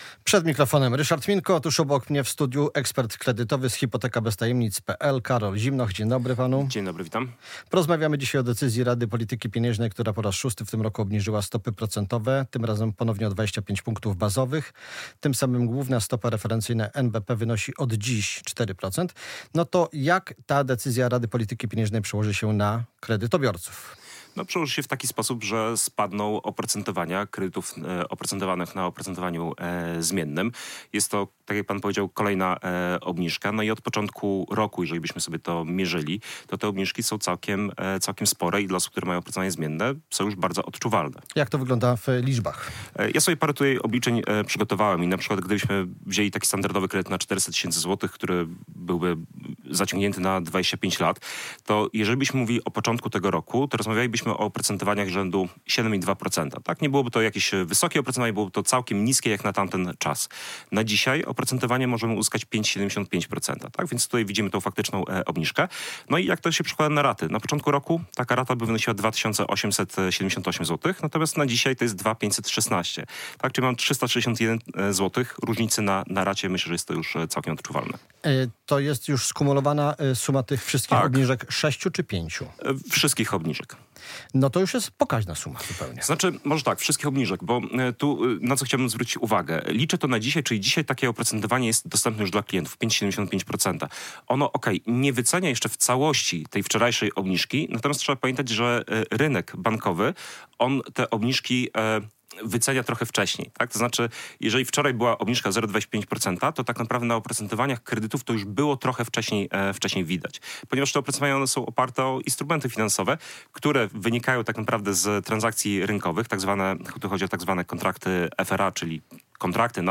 Polityka, gospodarka, zdrowie, nauka, sport - codzienna rozmowa z gościem Polskiego Radia Białystok o wszystkich najważniejszych sprawach dotyczących nie tylko regionu.